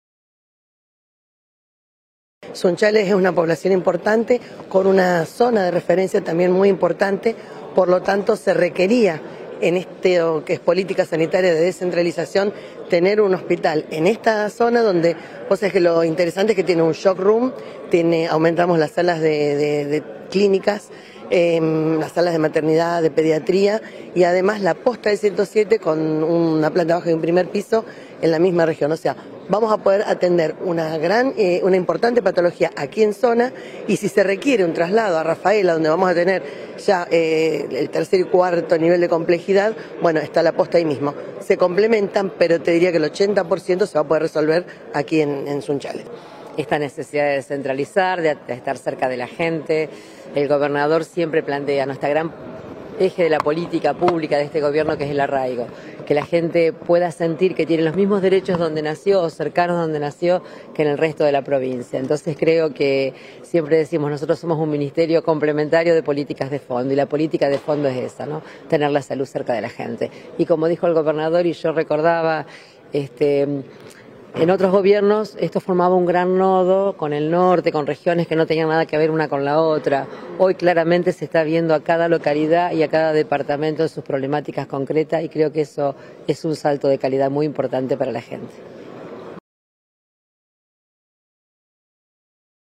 Declaraciones Martorano y Frana